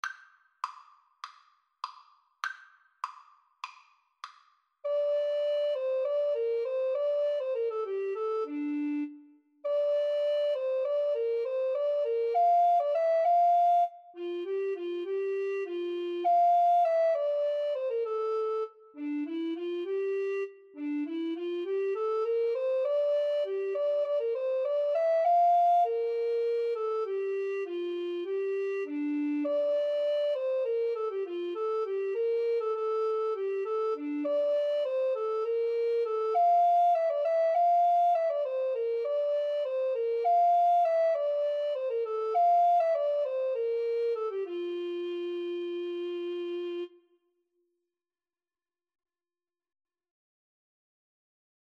Classical (View more Classical Recorder Duet Music)